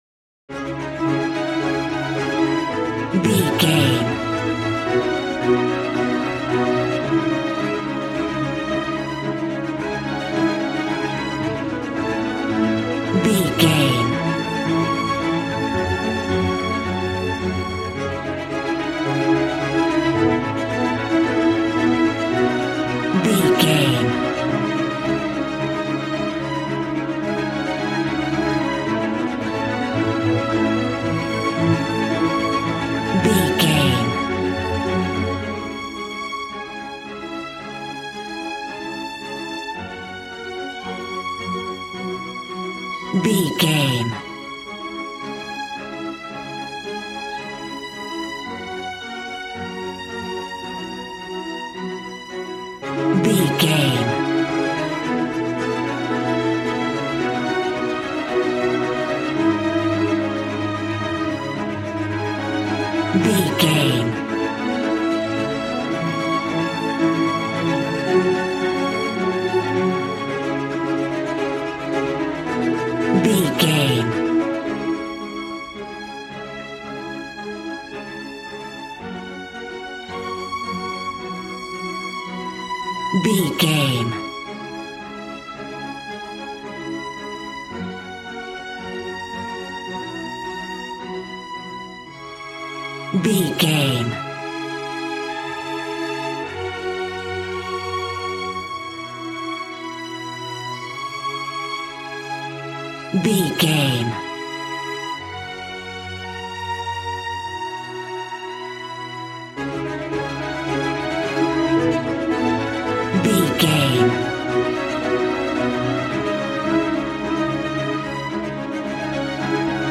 Regal and romantic, a classy piece of classical music.
Ionian/Major
regal
strings
brass